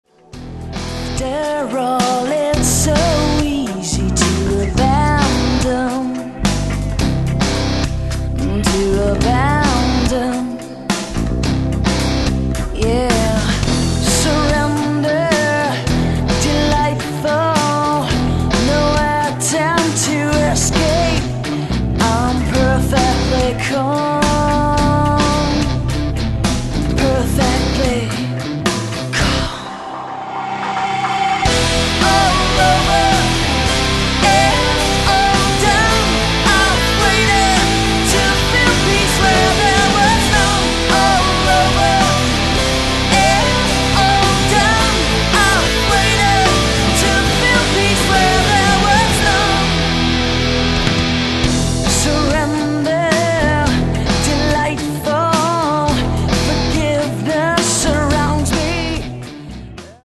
Genre: metal moderne